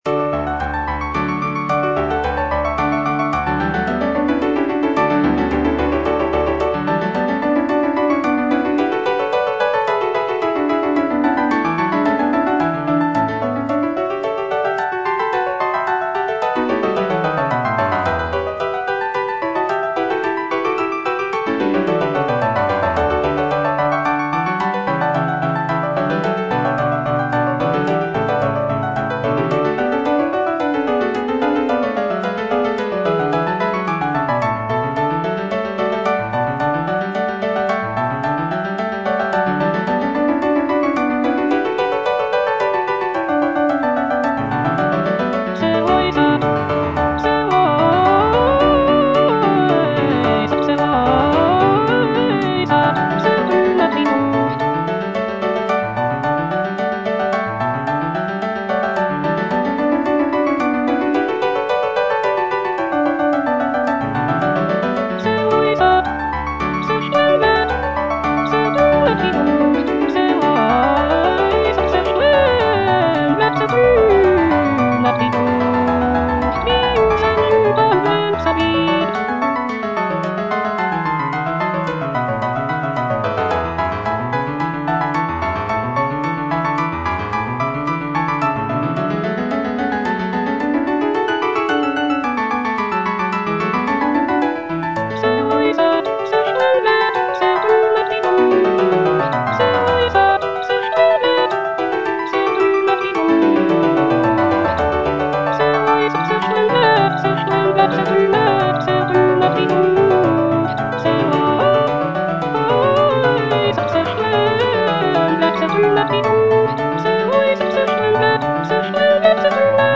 Cantata
Alto (Voice with metronome) Ads stop